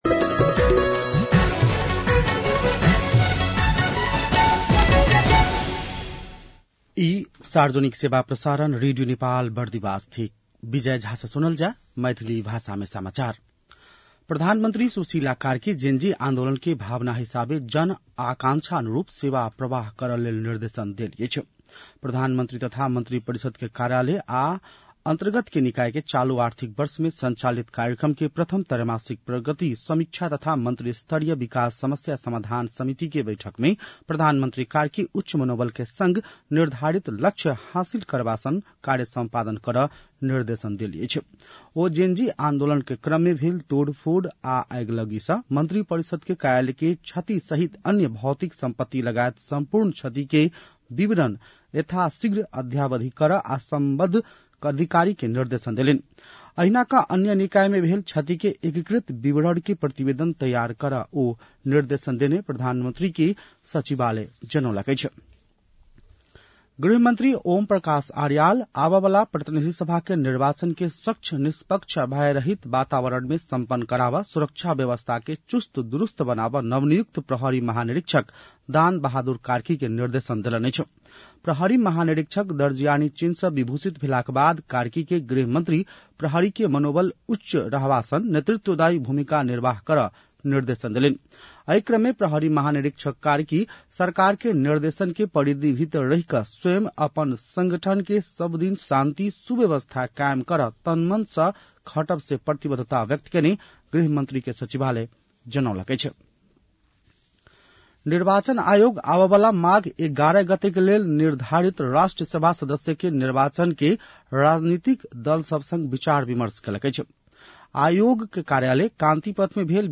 मैथिली भाषामा समाचार : २७ कार्तिक , २०८२
6-pm-maithali-news-7-27.mp3